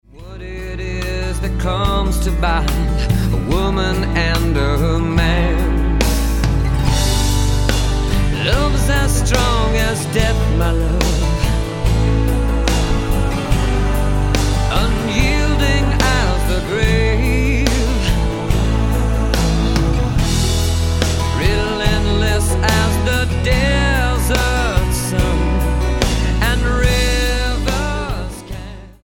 Roots/Acoustic